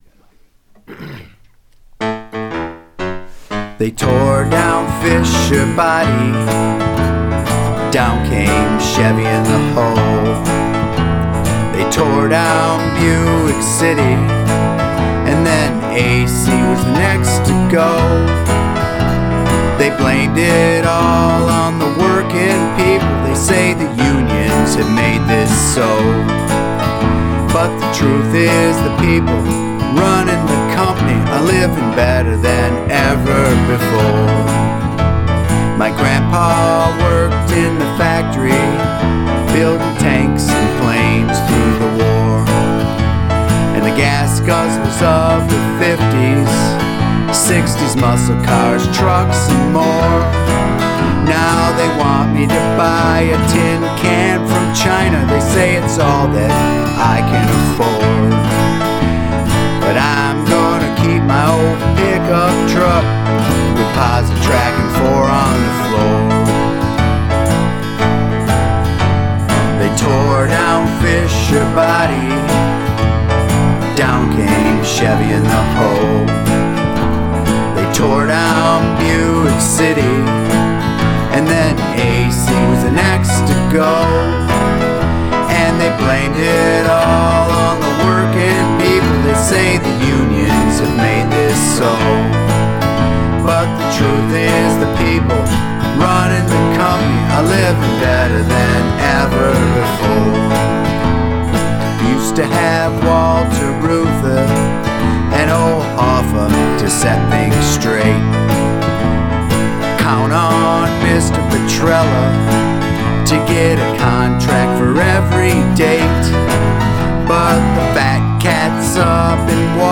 Genres: Folk, Acoustic